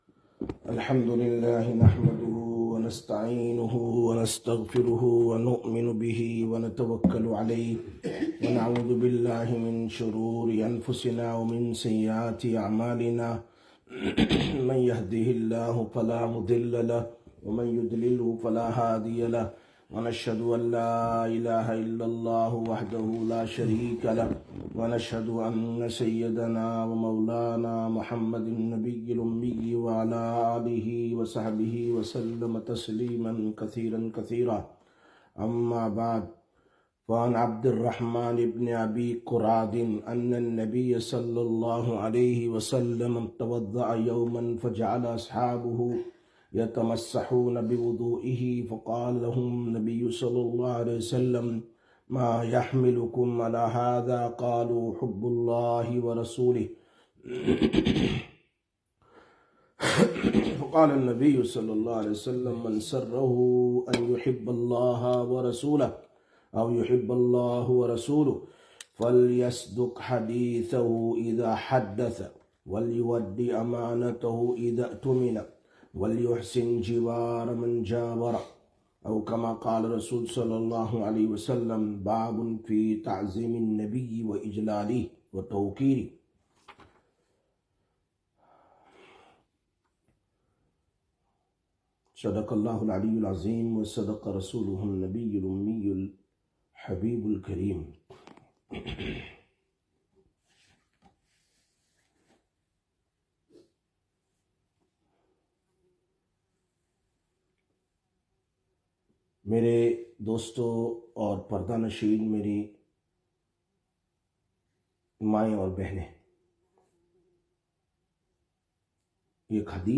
25/06/2025 Sisters Bayan, Masjid Quba